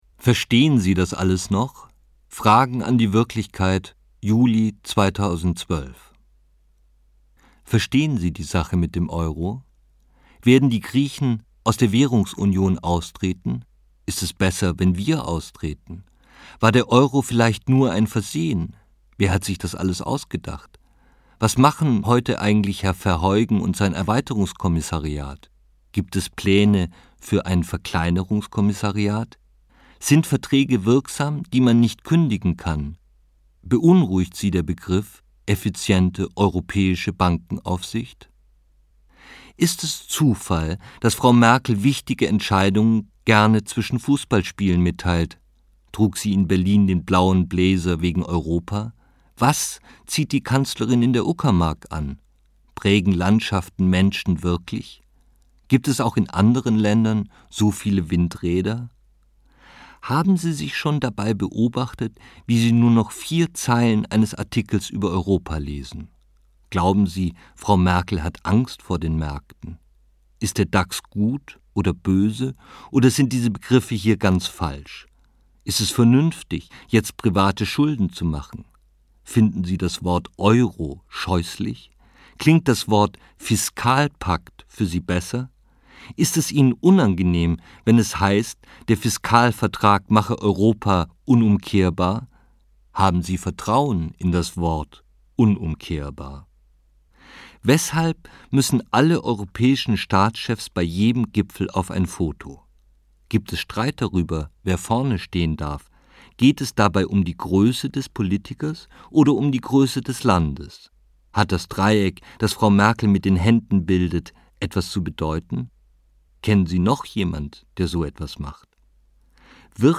Die Würde ist antastbar 3 CDs Ferdinand Von Schirach (Sprecher) Ferdinand Von Schirach (Autor) Audio-CD 2014 | 3.